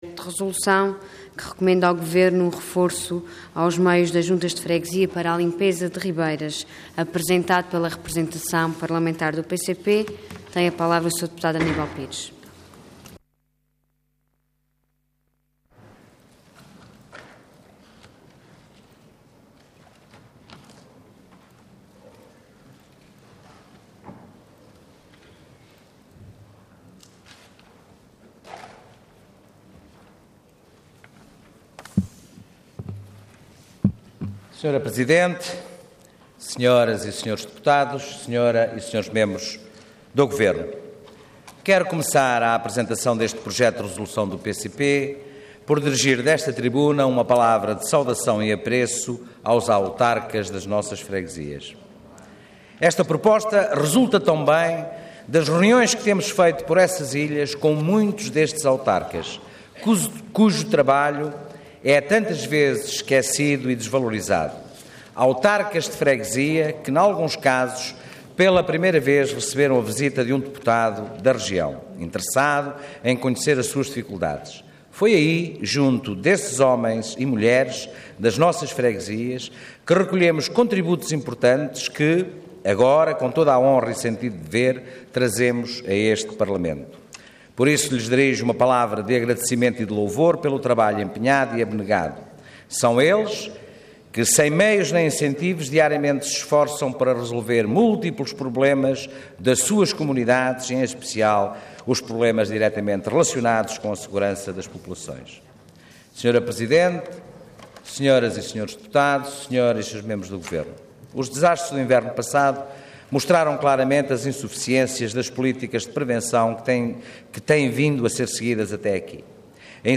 Detalhe de vídeo 15 de maio de 2013 Download áudio Download vídeo Diário da Sessão Processo X Legislatura Recomenda ao Governo que reforce os meios das Juntas de Freguesia para a limpeza de ribeiras e realize um plano especial de ações de limpeza e prevenção de riscos de cheias e deslizamentos. Intervenção Projeto de Resolução Orador Aníbal Pires Cargo Deputado Entidade PCP